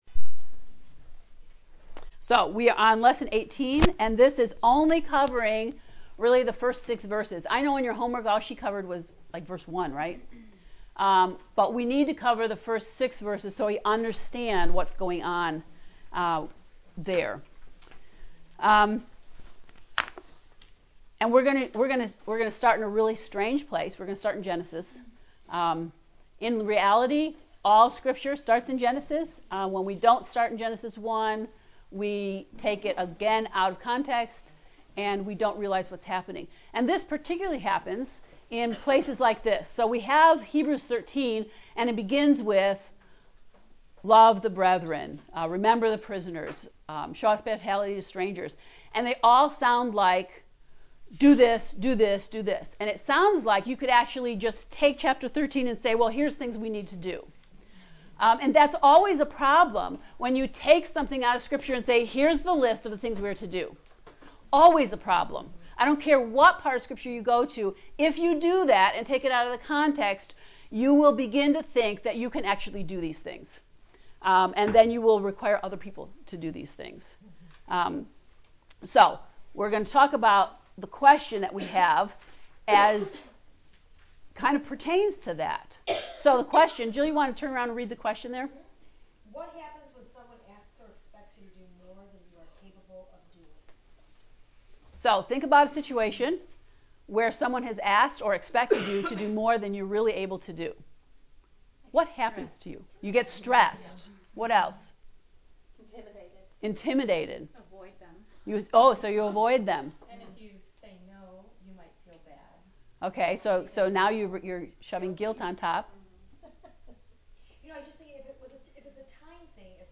To listen to the lecture of lesson 18 “Re-Creating with Grace” click below:
heb-ii-lecture-18.mp3